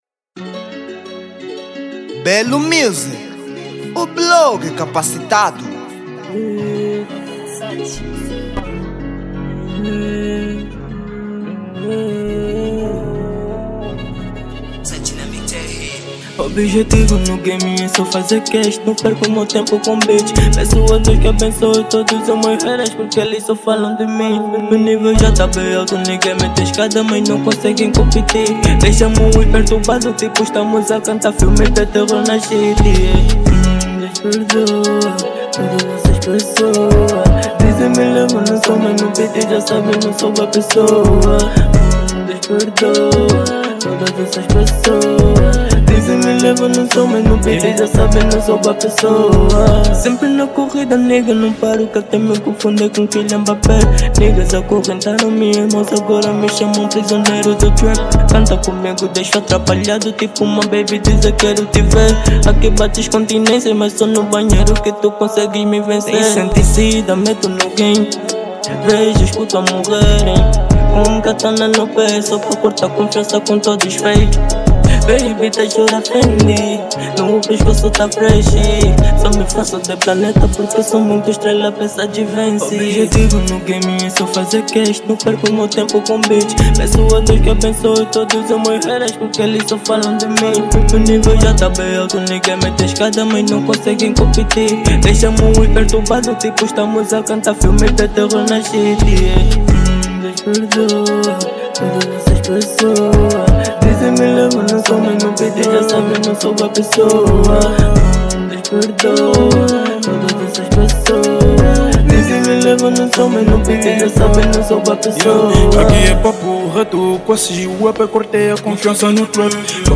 Género: TRAP